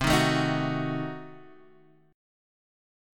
Ebdim/C Chord
Eb-Diminished-C-8,6,7,8,7,x.m4a